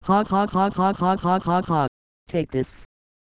Worms speechbanks
laugh.wav